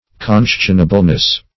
Search Result for " conscionableness" : The Collaborative International Dictionary of English v.0.48: Conscionableness \Con"scion*a*ble*ness\, n. The quality of being conscionable; reasonableness.